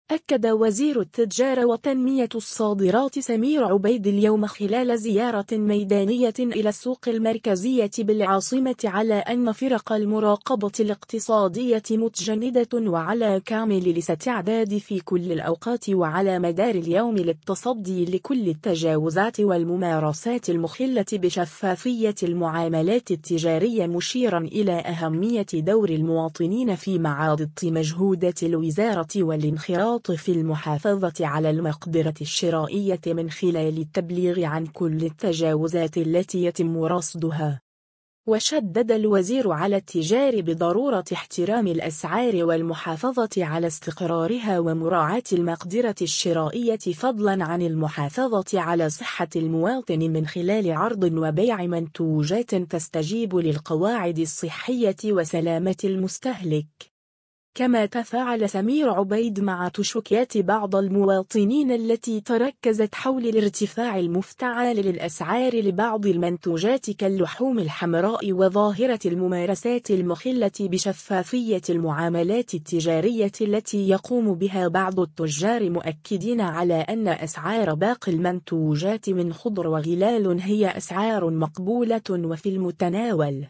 أكد وزير التجارة وتنمية الصادرات سمير عبيد اليوم خلال زيارة ميدانية إلى السوق المركزية بالعاصمة على أن فرق المراقبة الاقتصادية متجندة وعلى كامل الاستعداد في كل الأوقات وعلى مدار اليوم للتصدي لكل التجاوزات والممارسات المخلة بشفافية المعاملات التجارية مشيرا إلى أهمية دور المواطنين في معاضدة مجهودات الوزارة والانخراط في المحافظة على المقدرة الشرائية من خلال التبليغ عن كل التجاوزات التي يتم رصدها.